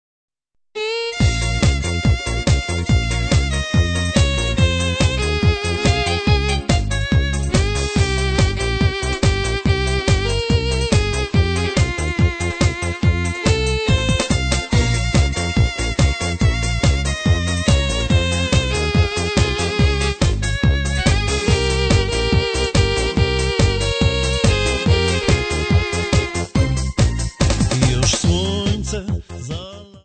3 CD set of Polish Folk Songs.